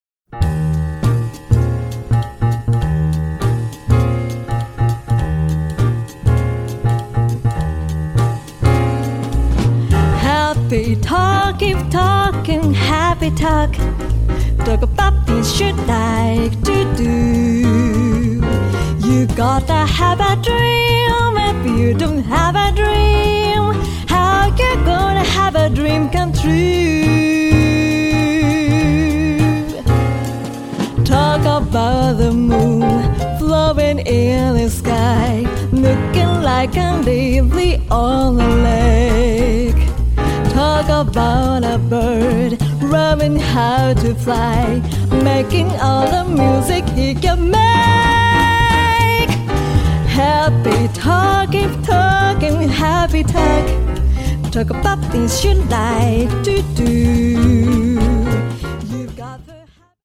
巨匠のあまりにも有名な歌唱を踏まえつつも自分のフレージングが生きている魅力ある一曲。